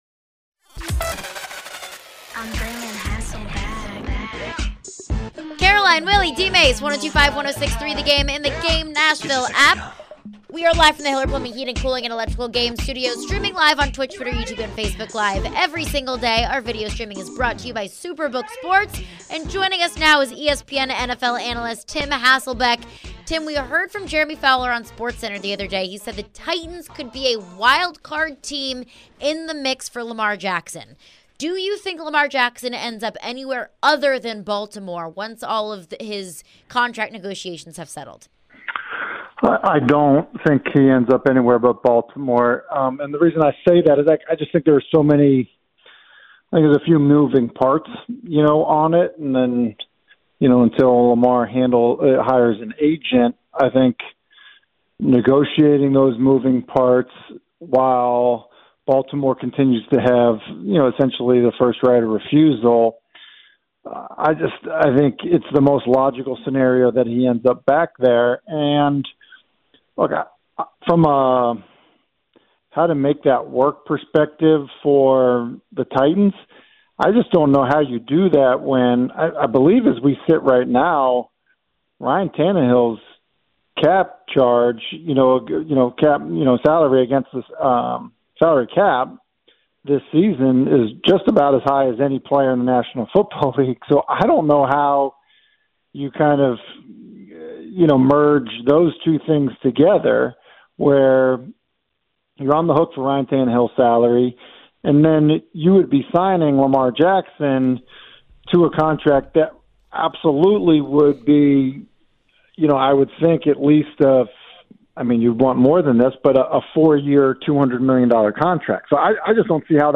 Tim Hasselbeck Interview (3-22-23)
ESPN NFL Analyst Tim Hasselbeck joins for his weekly visit discussing the latest in the NFL & the Titans.